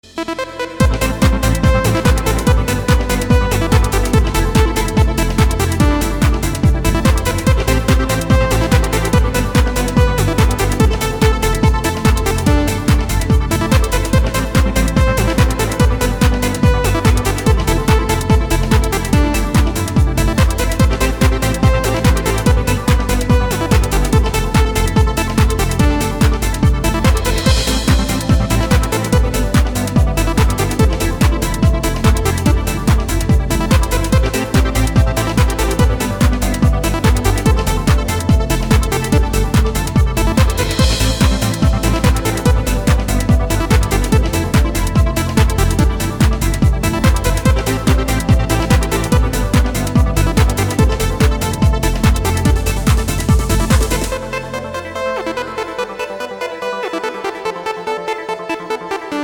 • Качество: 256, Stereo
dance
Electronic
электронная музыка
без слов
club
Trance